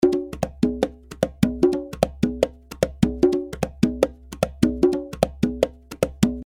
150 BPM conga loops (13 variations)
Real conga loops played by professional percussion player at 150 BPM.
The conga loops were recorded using 3 microphones,
(AKG C-12 VR , 2 x AKG 451B for room and stereo).